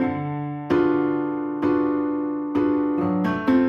Index of /musicradar/gangster-sting-samples/130bpm Loops
GS_Piano_130-D2.wav